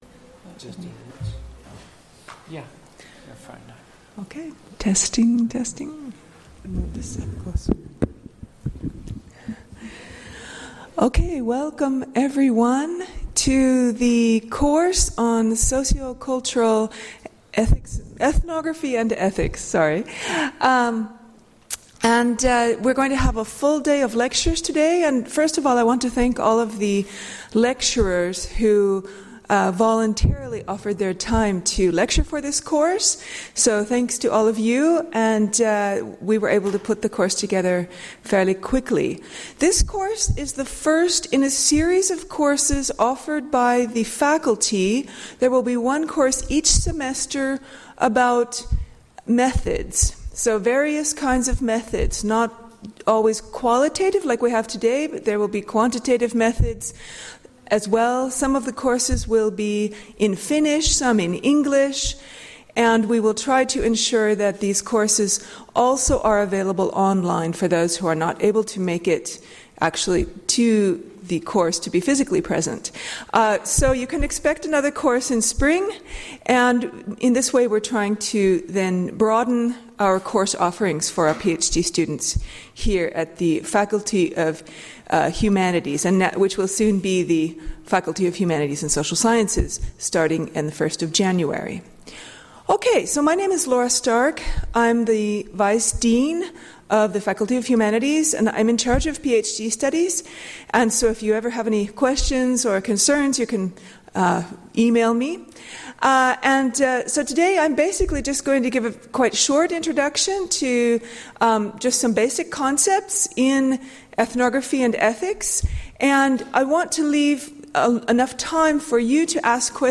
Lecture 17.11.2016 - Part 1 — Moniviestin